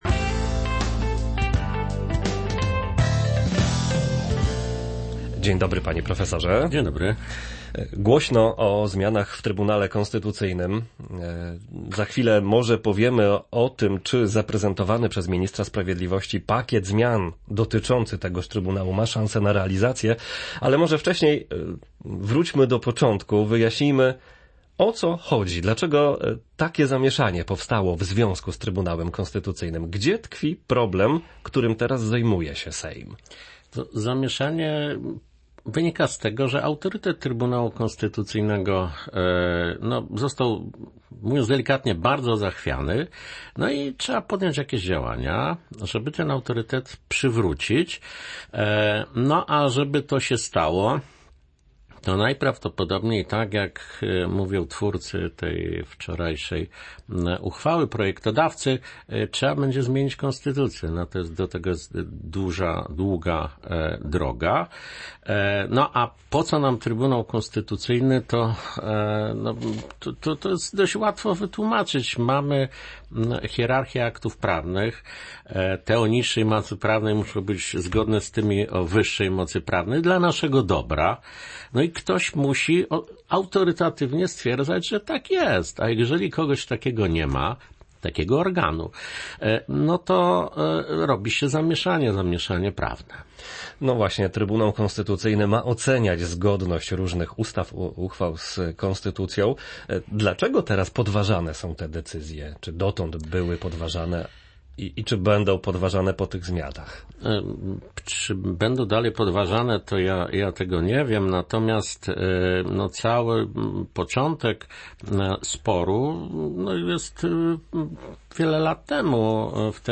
Nasz gość przypomina, że spór rozpoczął się jeszcze przed wyborami w 2015 roku, kiedy kształtowano skład Trybunału Konstytucyjnego i powołano pięciu, a nie trzech sędziów.